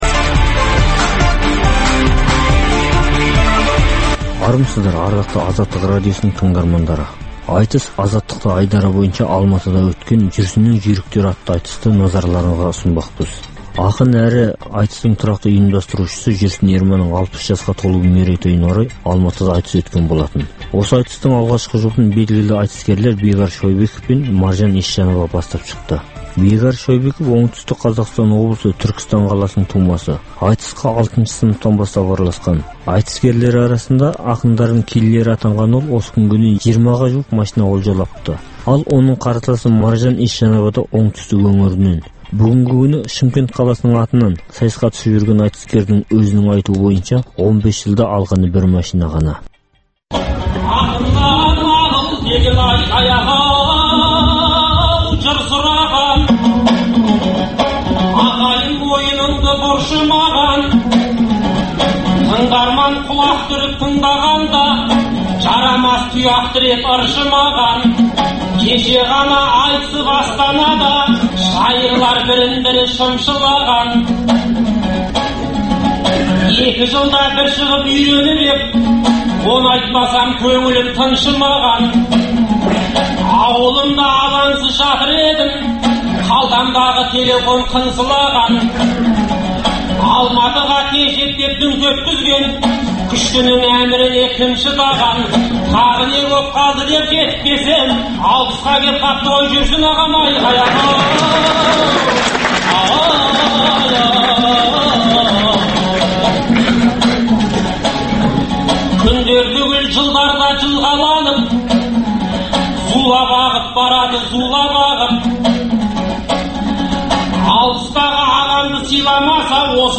Ақпан айында Алматыдағы Ғабит Мүсірепов атындағы Жастар мен балалар театрында «Жүрсіннің жүйріктері» атты айтыс болып өтті.
Айтысқа жиналған халық 470 орындық театрдың ішіне симай кетті. Ішке кіре алмай қайтқандардың қарасы да бір қауым ел.